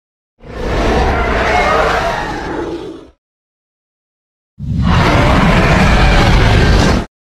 Jurassic World Carno VS Disney Sound Effects Free Download